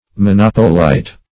Monopolite \Mo*nop"o*lite\, n.